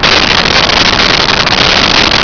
Sfx Surface Metalgrid Loop
sfx_surface_metalgrid_loop.wav